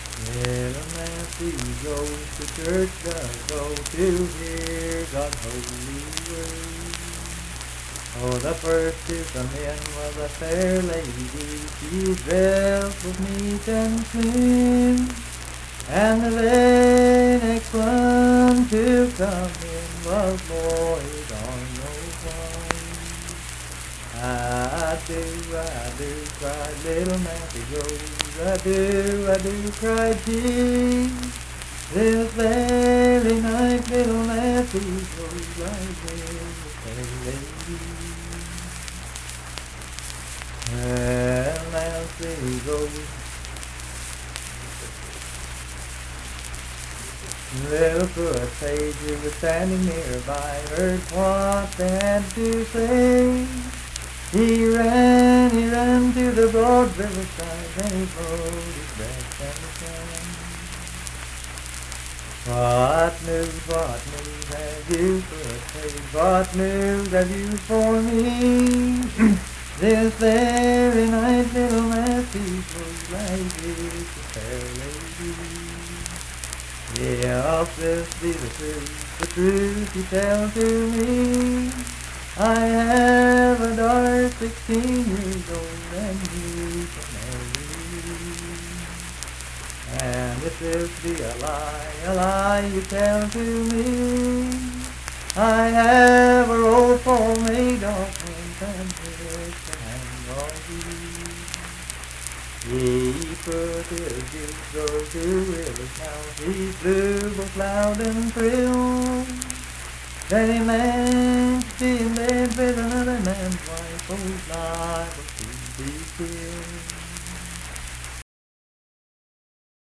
Unaccompanied vocal music performance
Voice (sung)
Sutton (W. Va.), Braxton County (W. Va.)